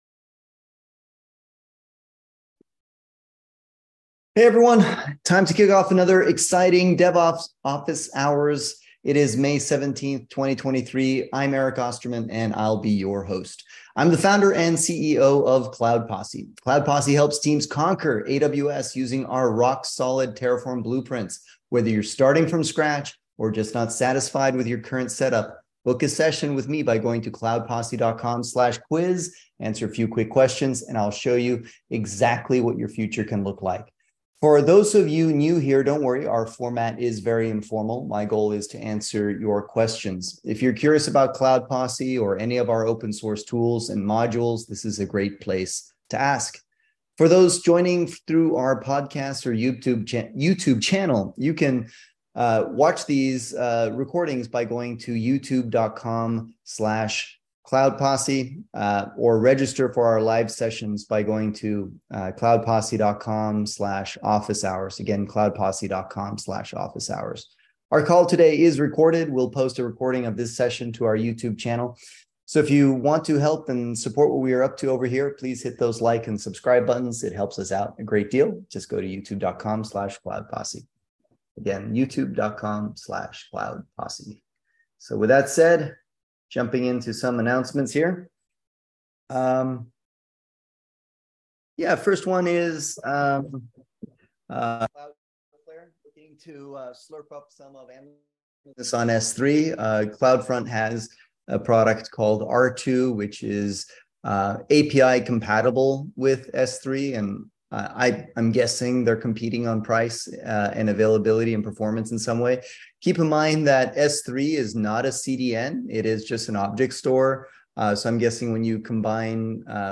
Cloud Posse holds public "Office Hours" every Wednesday at 11:30am PST to answer questions on all things related to DevOps, Terraform, Kubernetes, CICD. Basically, it's like an interactive "Lunch & Learn" session where we get together for about an hour and talk shop.